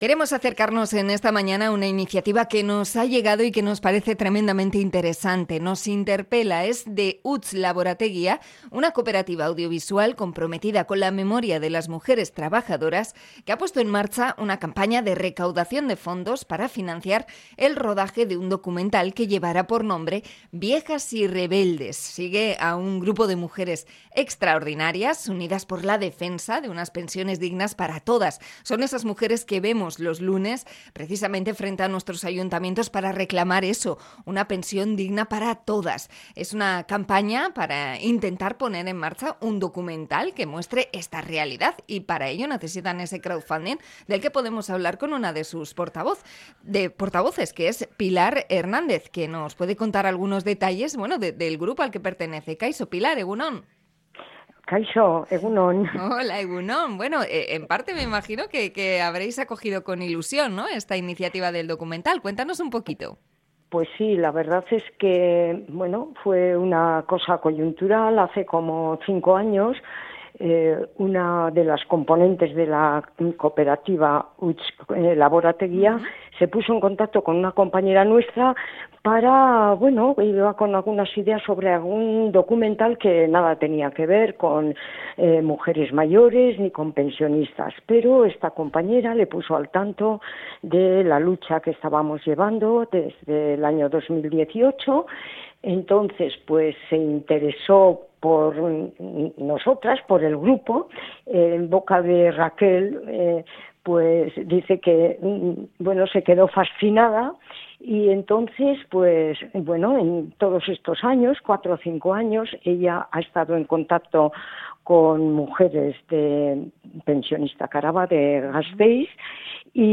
Entrevista al movimiento de mujeres pensionistas